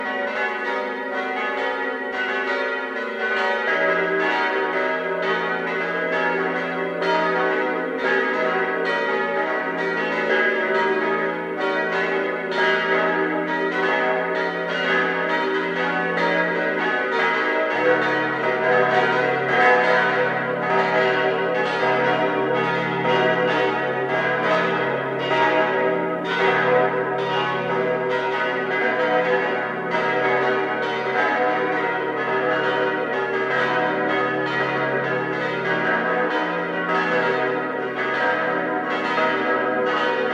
Die Glocken
Volles Geläut
• Die Stadtpfarrkirche Herz Jesu besitzt sechs Glocken.